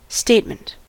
statement: Wikimedia Commons US English Pronunciations
En-us-statement.WAV